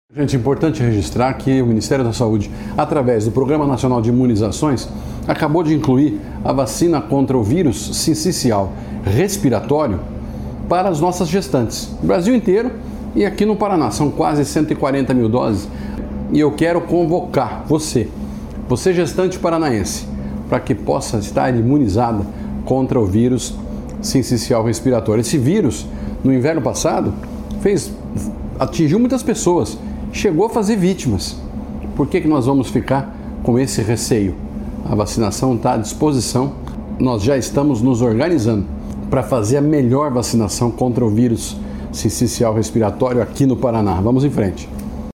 Sonora do secretário da Saúde, Beto Preto, sobre a nova vacina para o Vírus Sincicial Respiratório